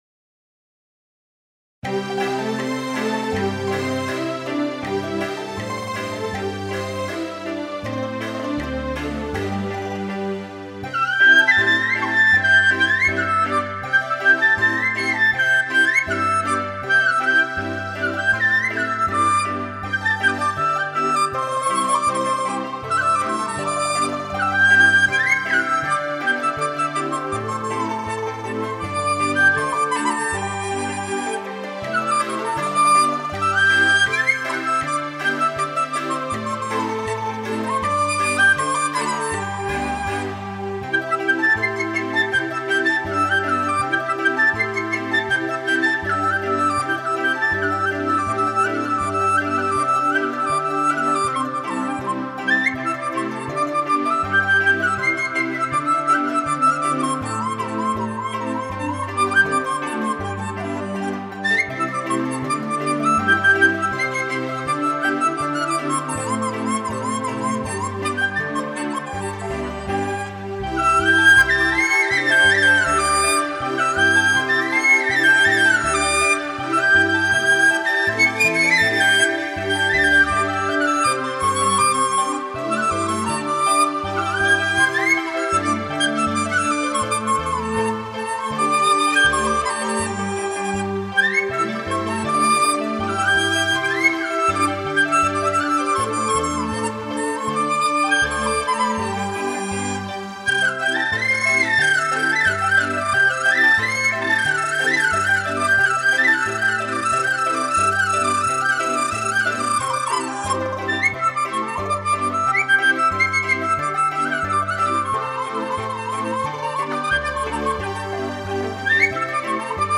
乐曲通过多样技巧三次变奏，旋律优美、清新、活泼，在风格上明显不同于圆润、婉转、流畅的江苏民歌，是北派笛曲中的小巧玲珑之精品。